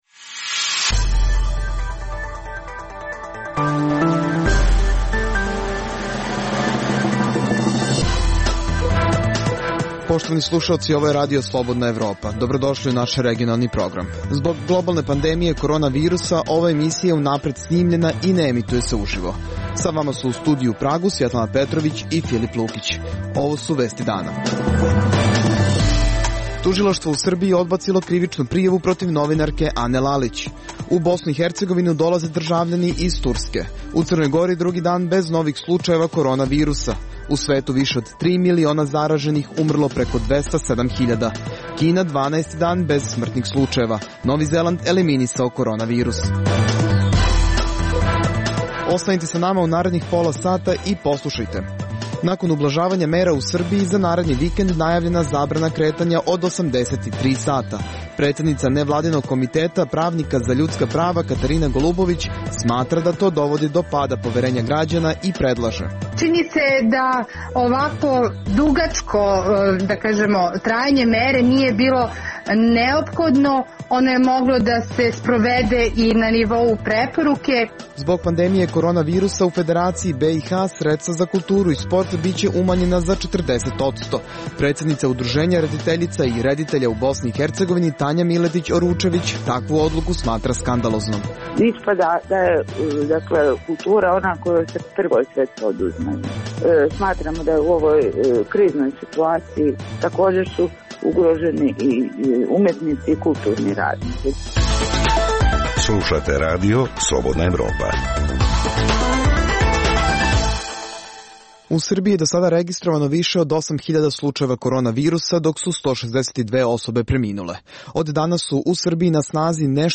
Zbog globalne pandemije korona virusa, ova emisija je unapred snimljena i ne emituje se uživo.